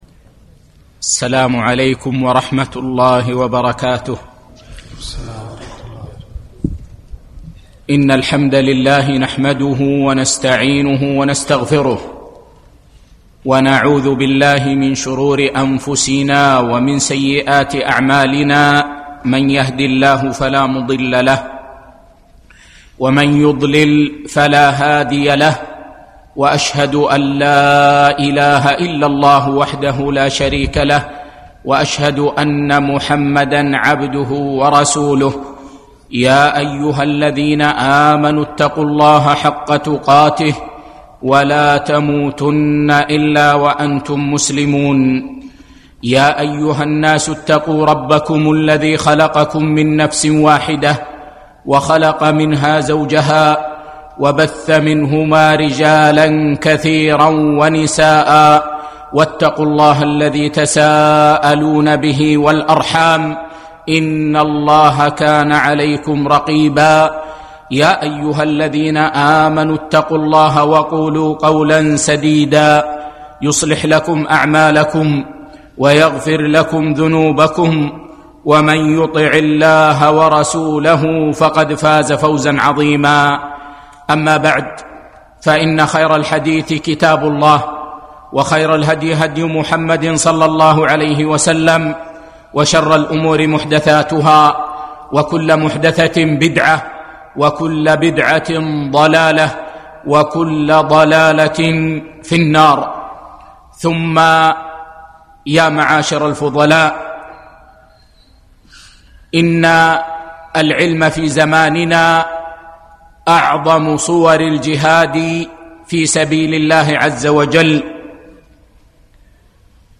يوم الجمعة 9 3 2018 مسجد صالح الكندري بعد صلاة العشاء